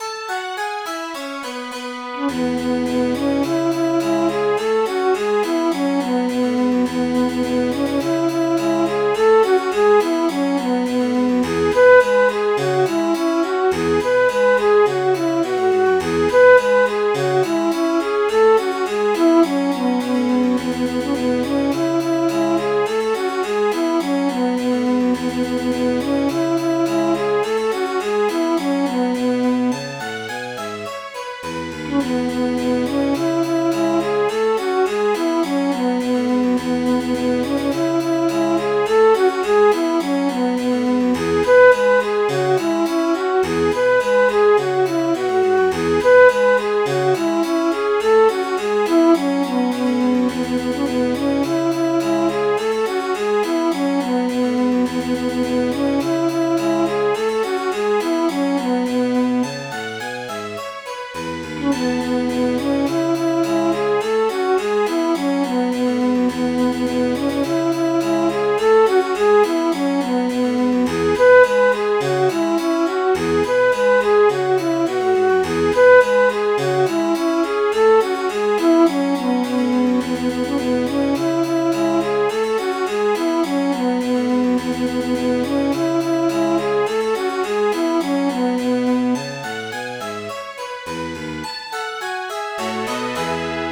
Midi File, Lyrics and Information to The Spanish Lady